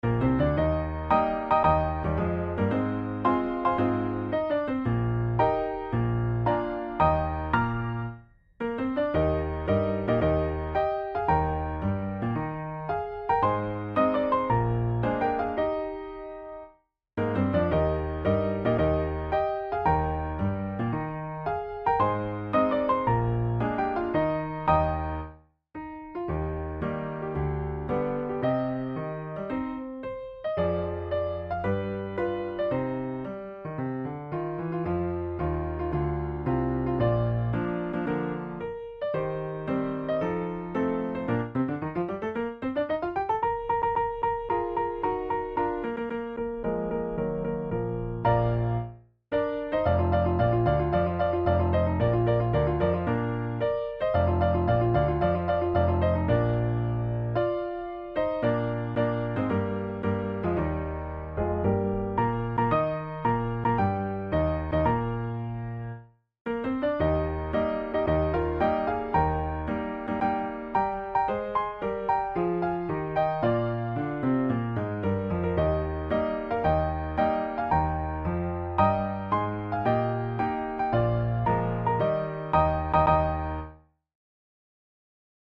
KeyE-flat major
Tempo112 BPM